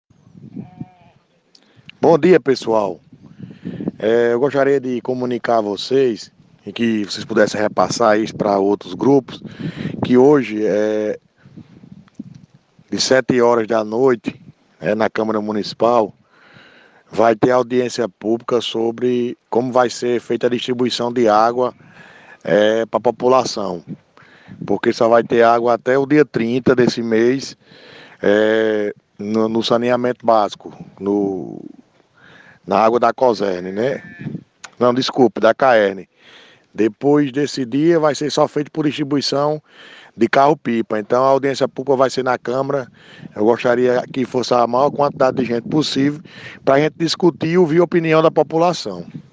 Ouça o Áudio do Edil: